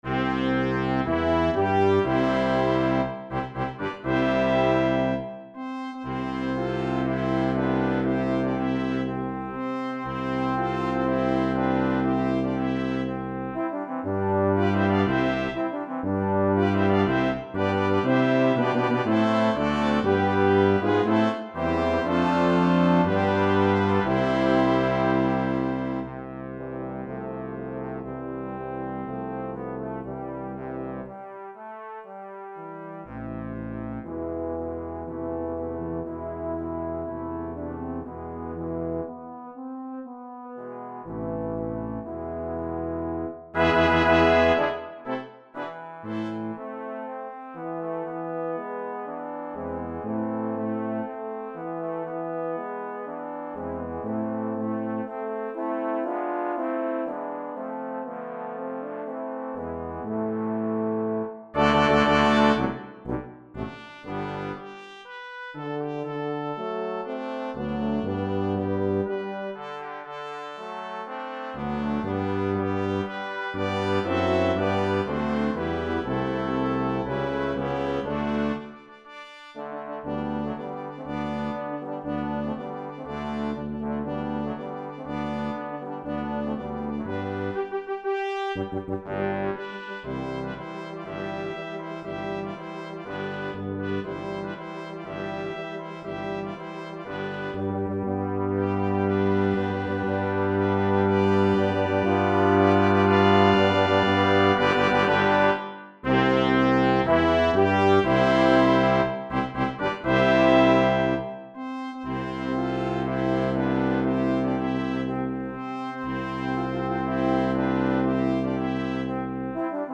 3 Trumpets
2 Horns in F
2 Trombones
Euphonium
Tuba
for Brass Nonet